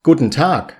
กู-เท็น-ทาก